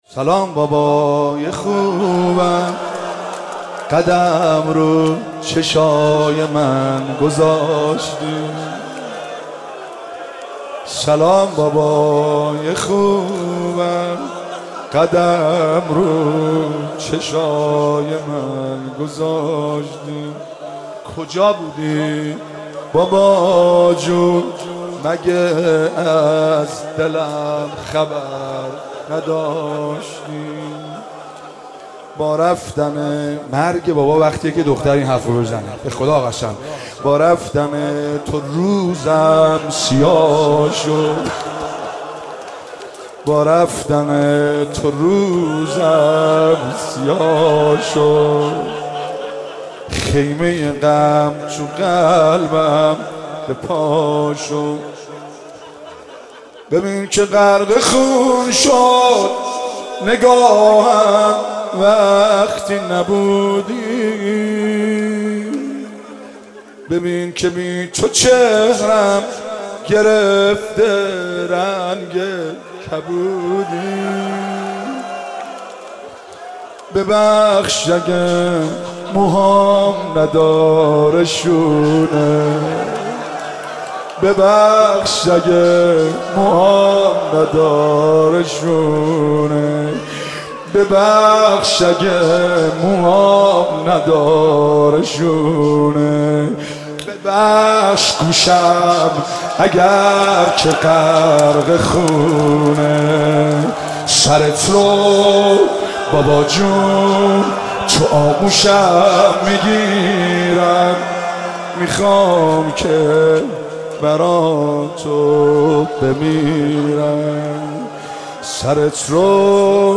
صوت مراسم شب سوم محرم ۱۴۳۷ هیئت فاطمیون ذیلاً می‌آید: